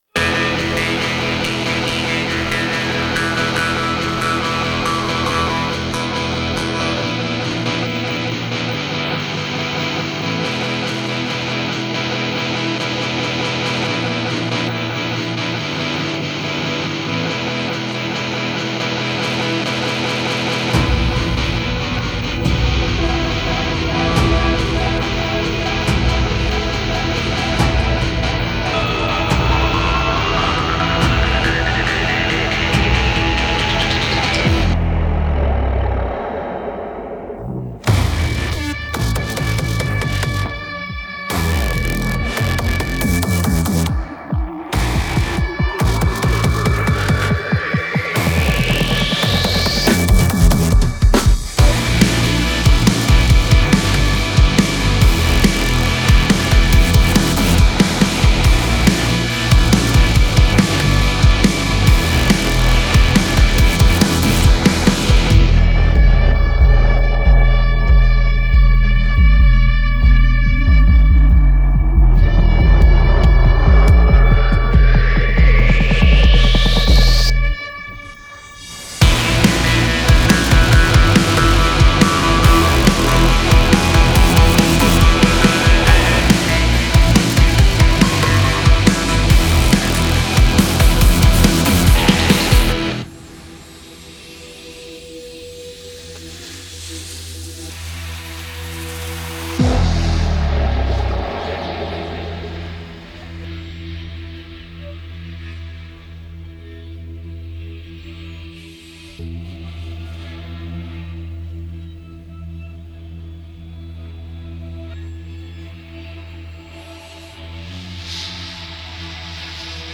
Качественный минус
башкирской этнической группы